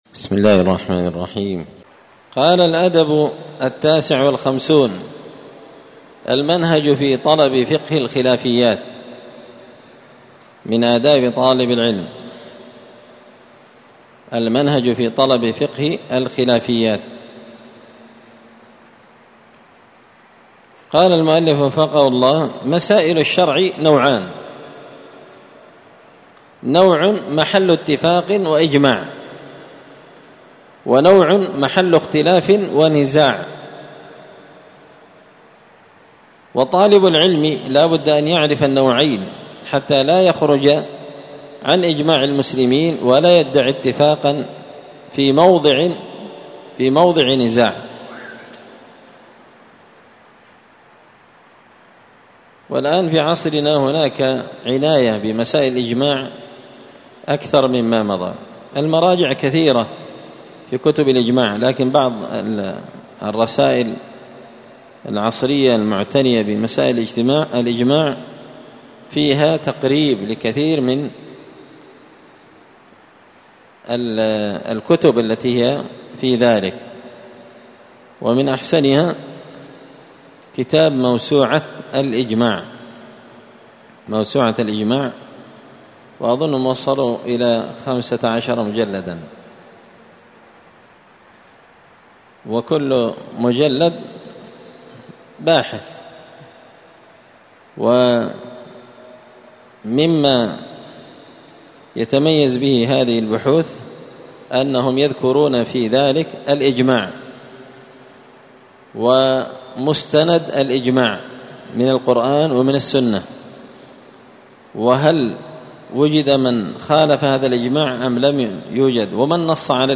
النبذ في آداب طالب العلم الدرس السابع والستون (67) الأدب التاسع والخمسون المنهج في طلب فقة الخلافيات